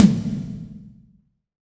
HEXTOMHI2.wav